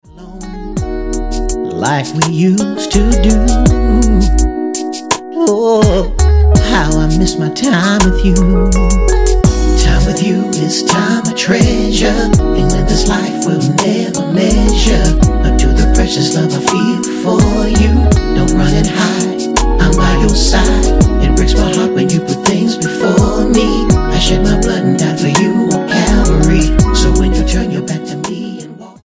R&B
Style: Gospel